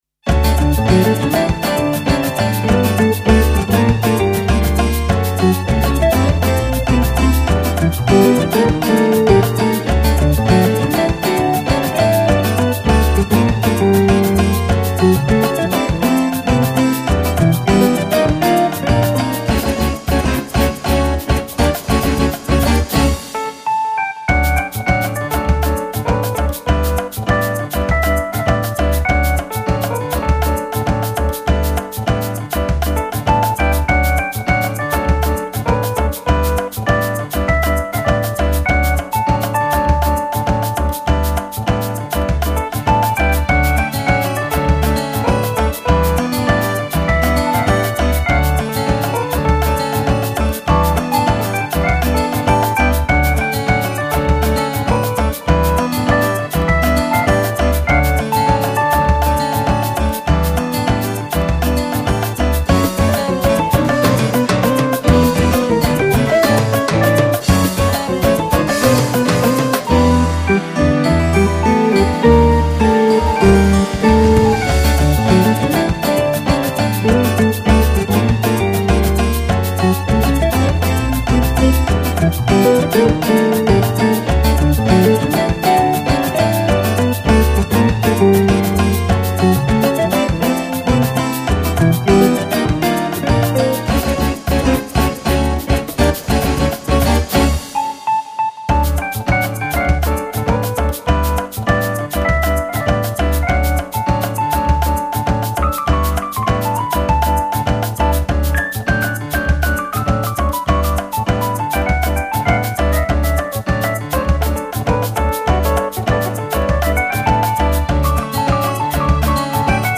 夏を感じさせ、ご機嫌フュージョンも。